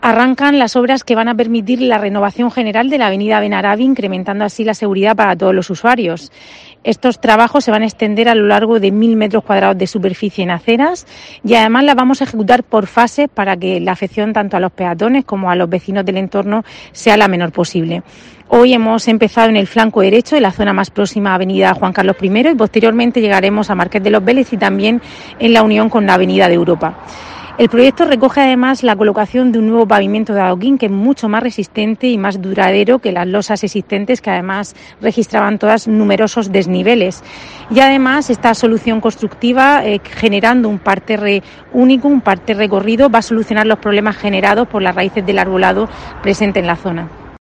Rebeca Pérez, vicealcaldesa y concejala de Fomento y Patrimonio